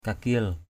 /ka-ki:l/ (d.) vĩ cầm = violon. violin.